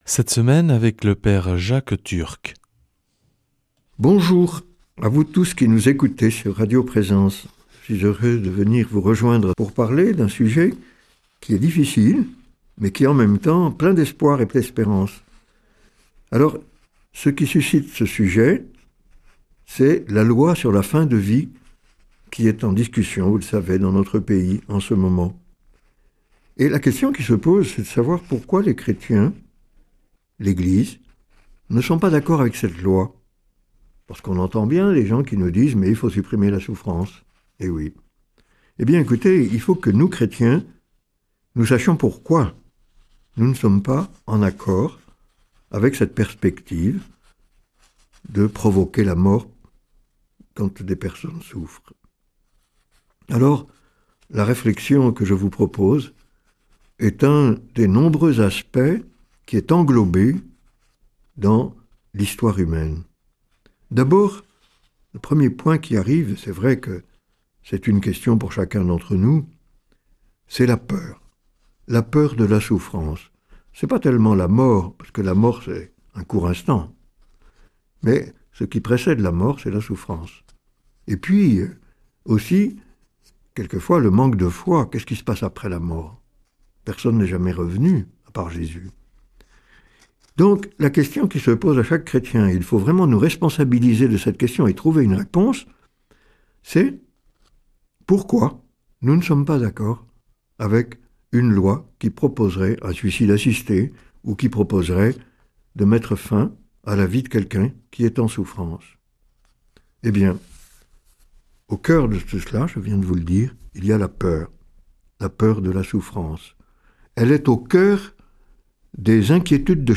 lundi 23 février 2026 Enseignement Marial Durée 10 min